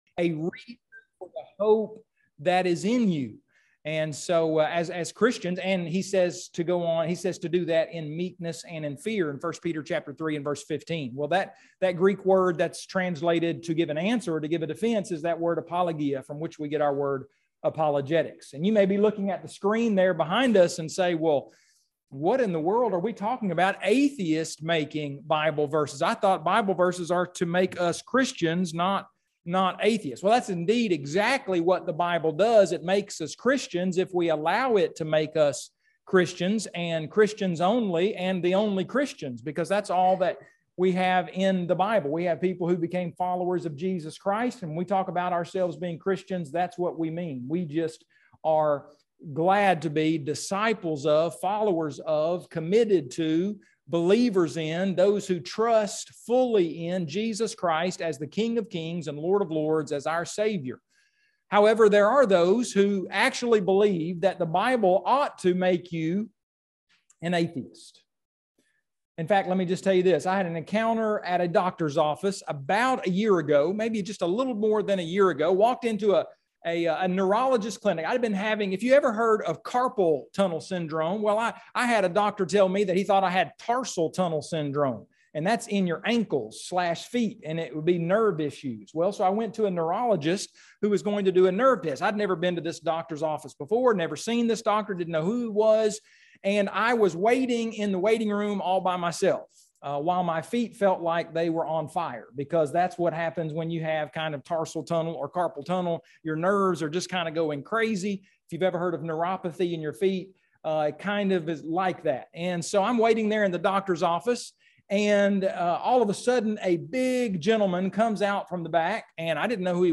9/11/2022 Gospel Meeting Lesson 1